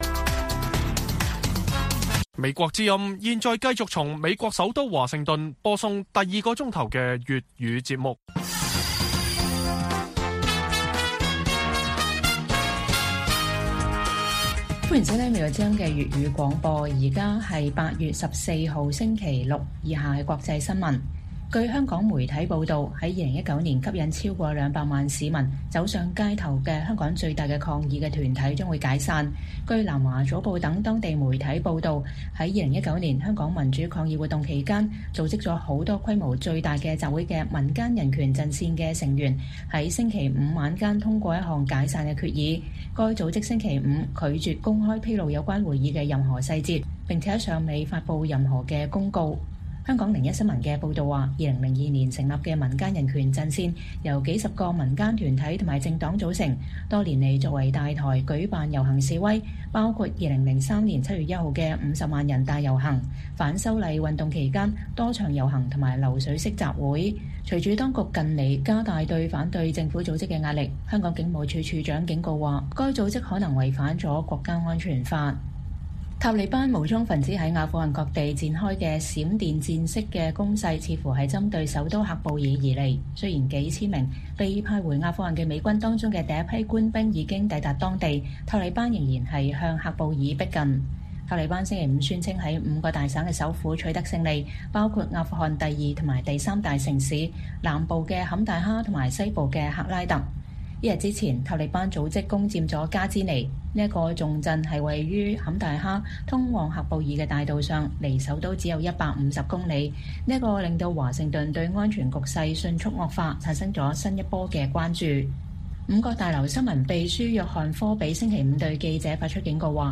粵語新聞 晚上10-11點港媒：香港民陣將解散